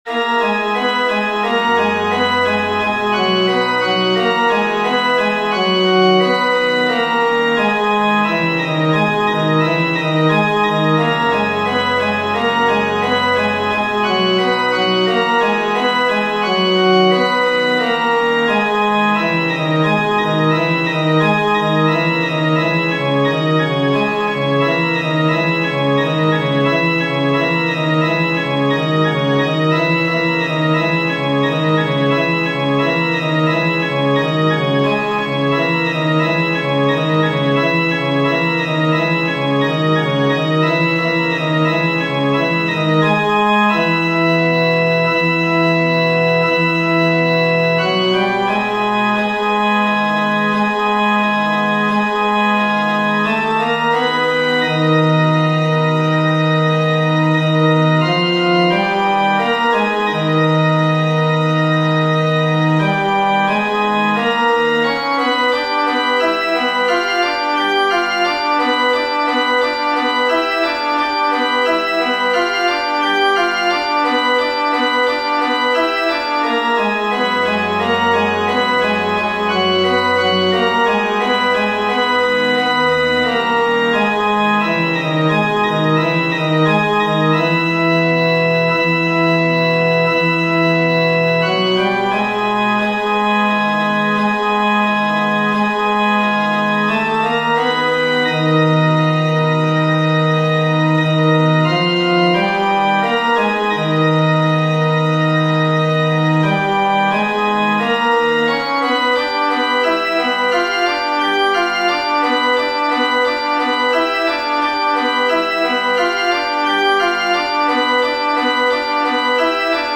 FF:HV_15b Collegium male choir
Rozlouceni-baryton.mp3